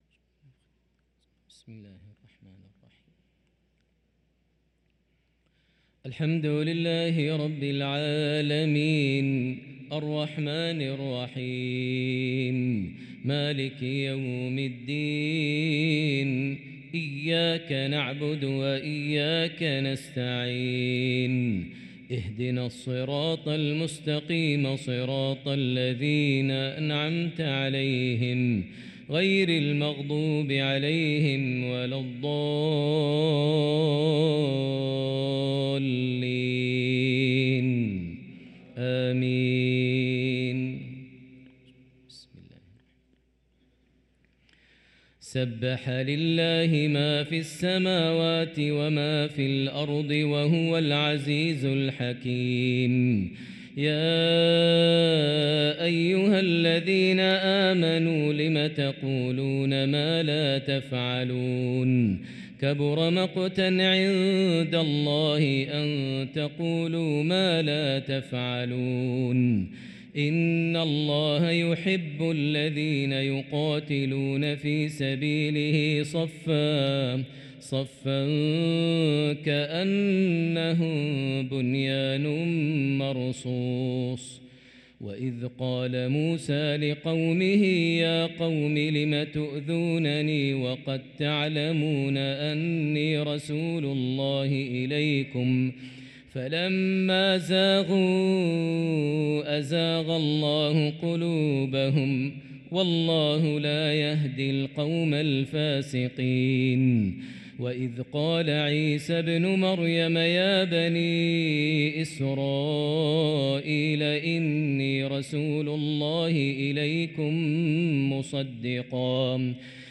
صلاة العشاء للقارئ ماهر المعيقلي 20 ربيع الآخر 1445 هـ
تِلَاوَات الْحَرَمَيْن .